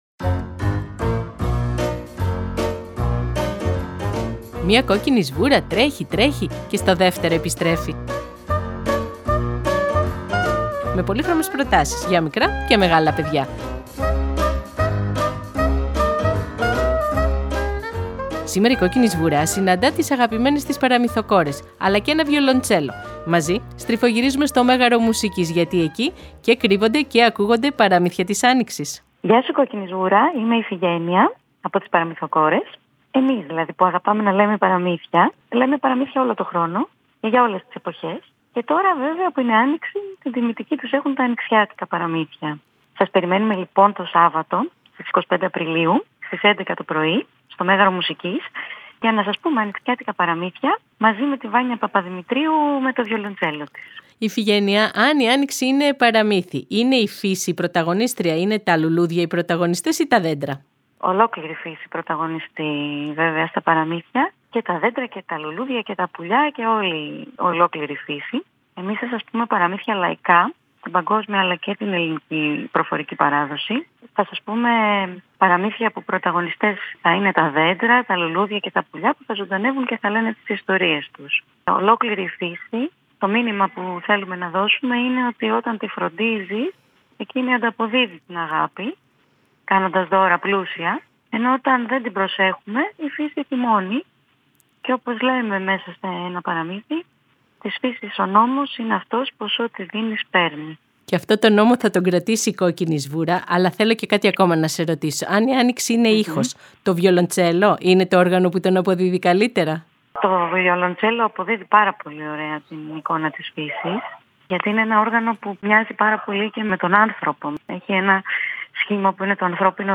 Δέντρα και λουλούδια χορεύουν με φυτά και λαϊκές παραδόσεις για το πρασίνισμα της Φύσης. Αυτά είναι τα Παραμύθια της Άνοιξης όπως τα αφηγούνται οι Παραμυθοκόρες στην Κόκκινη Σβούρα και μαζί στριφογυρίζουν στο Μέγαρο Μουσικής.